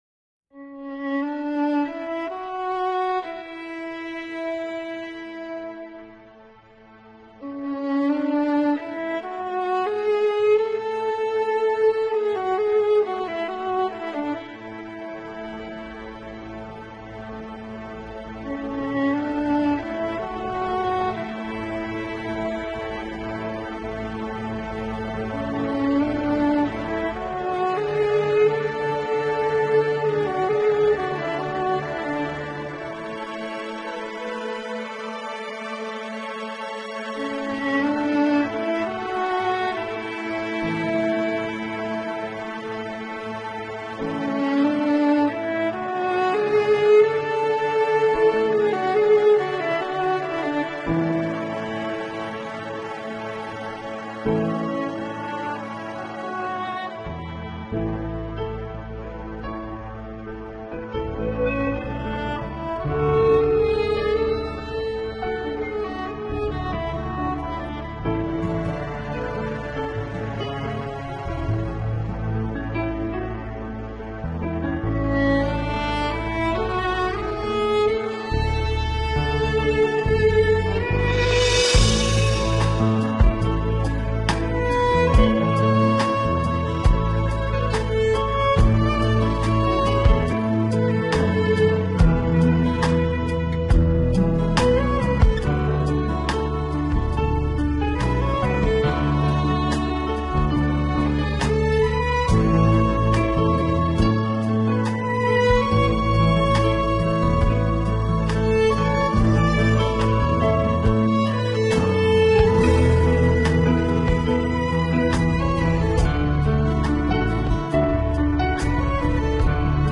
Фонограмма